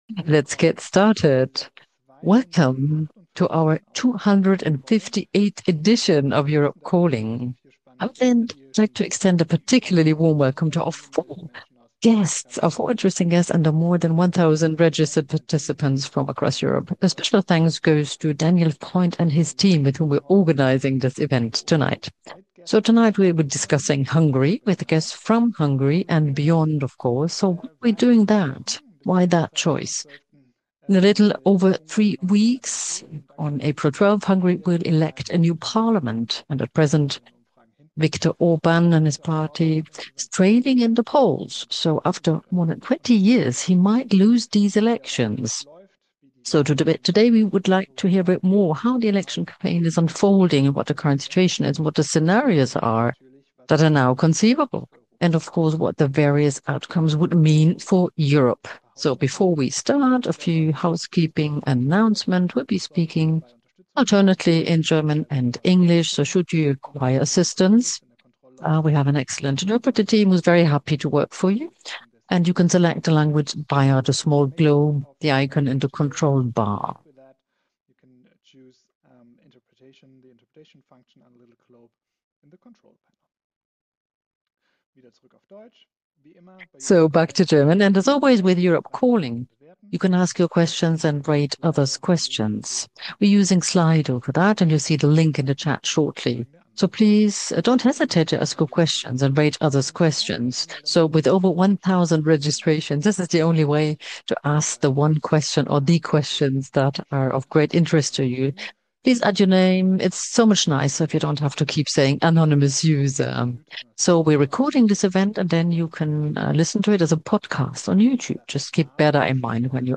Anton Hofreiter MP, Chair of the European Affairs Committee